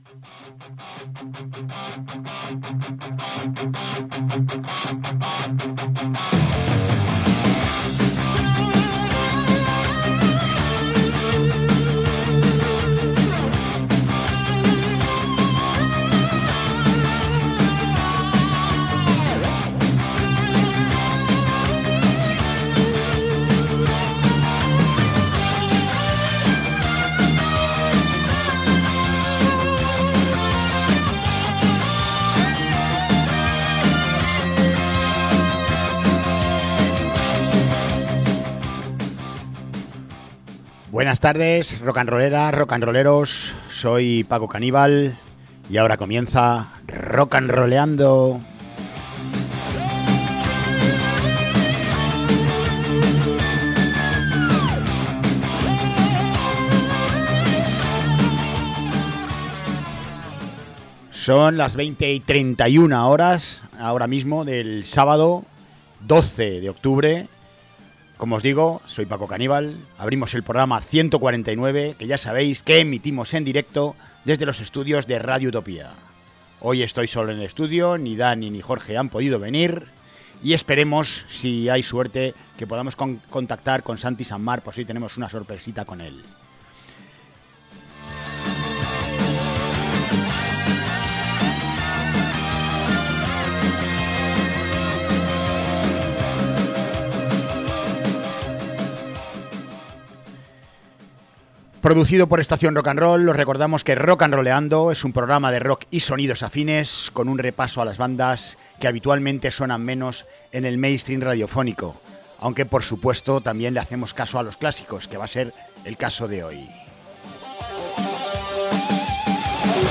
Facturan Blues rural, trasformado de forma beligerante hacia el folk, funk y rock and roll, tocado siempre con accesorios e instrumentos tradicionales consiguiendo un country blues clásico.